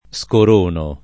scoronare v.; scorono [ S kor 1 no ]